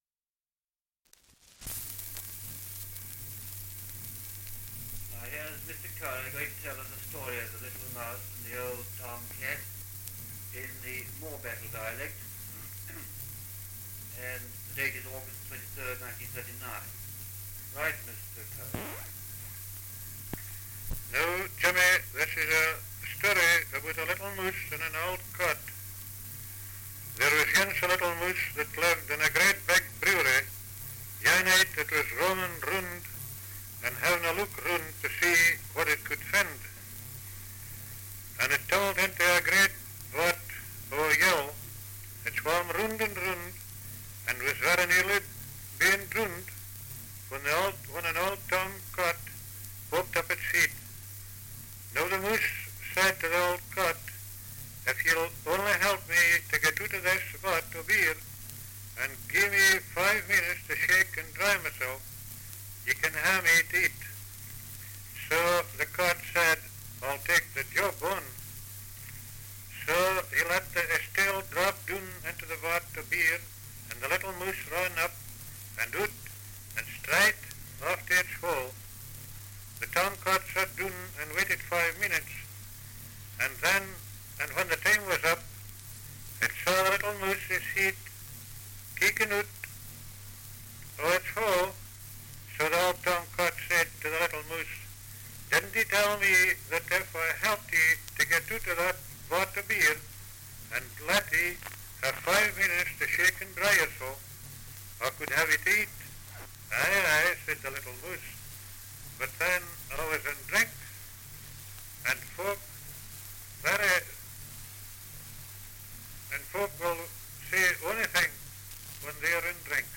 2 - Dialect recording in Morebattle, Roxburghshire
78 r.p.m., cellulose nitrate on aluminium